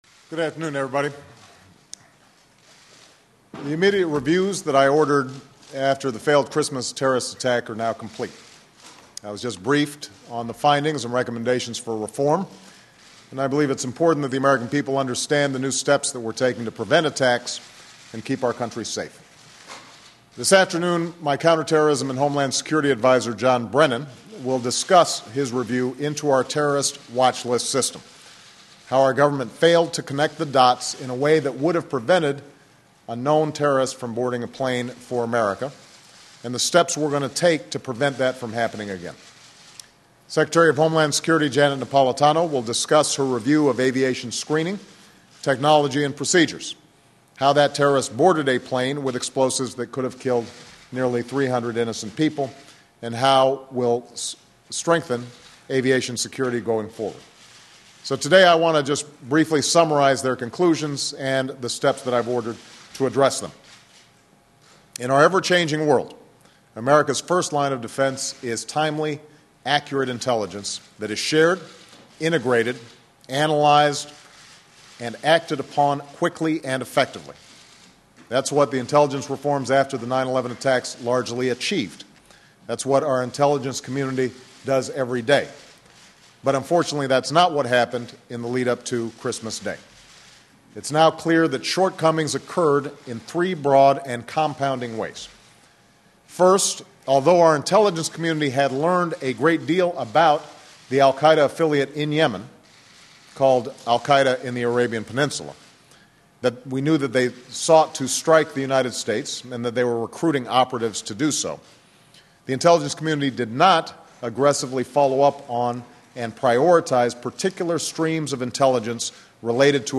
2010 Share President Obama speaks Thursday about the attempted terrorist attack on a U.S.-bound airliner on Christmas Day. He announced that there will be new measures the government takes to prevent terrorist attacks.